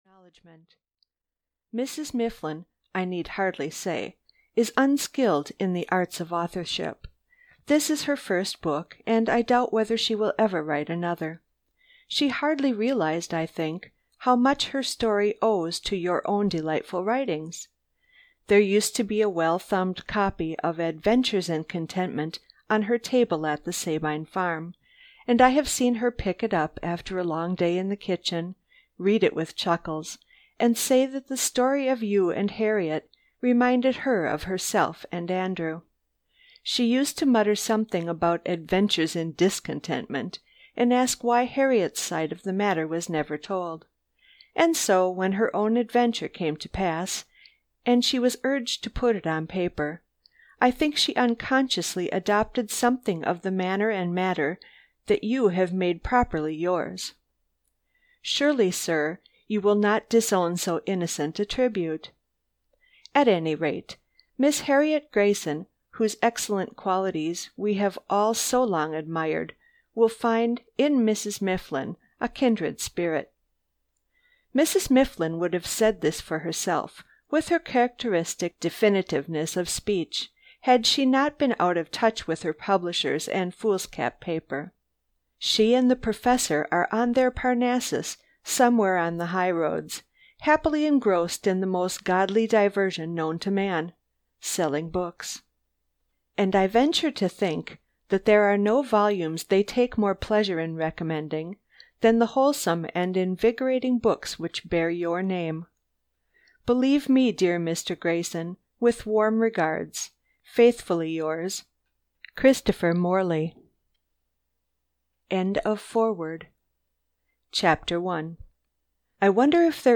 Parnassus on Wheels (EN) audiokniha
Ukázka z knihy